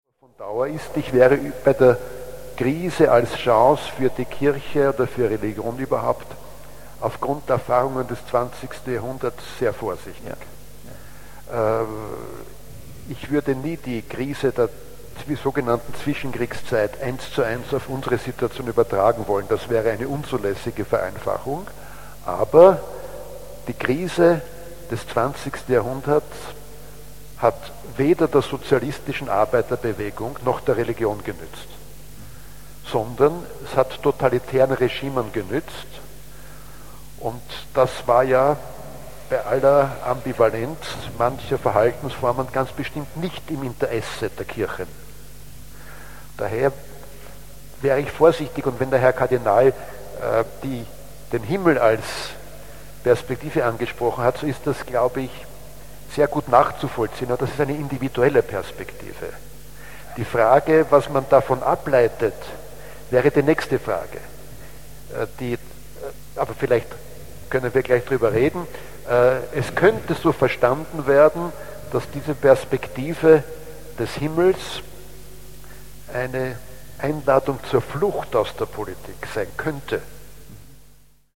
Sprech-CDs
Über den Auftrag von Religion und Politik zwischen Individualismus, Egoismus und Solidarität diskutierten am 5. Juni 2009 in der Kalvarienbergkirche Kardinal Christoph Schönborn und der Politikwissenschafter Anton Pelinka.